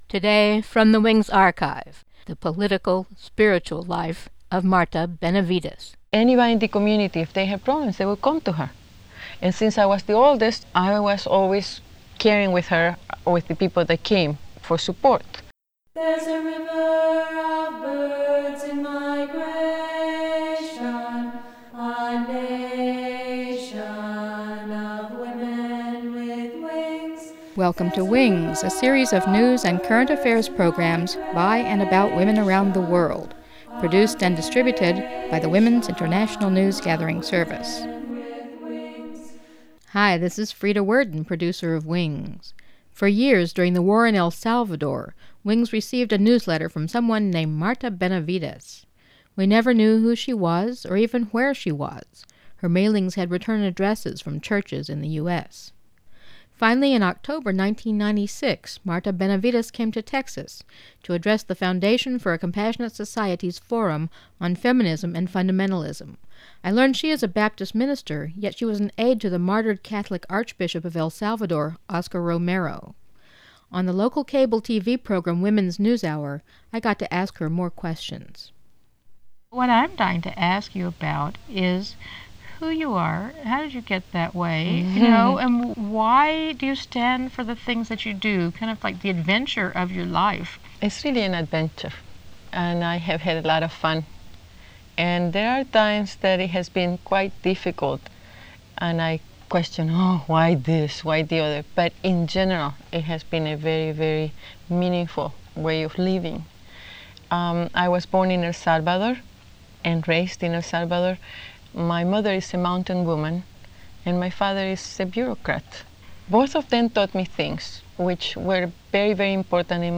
Mono
Interview